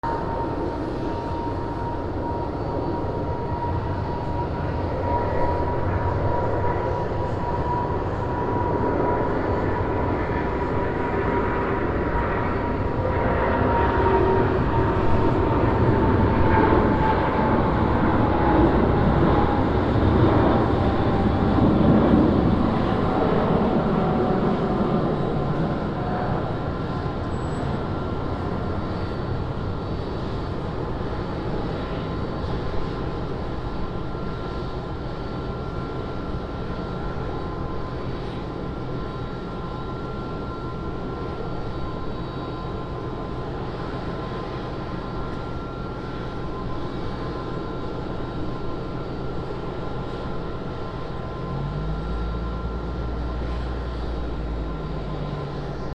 飛行機 離陸
/ E｜乗り物 / E-80 ｜飛行機・空港
416 NT4 mix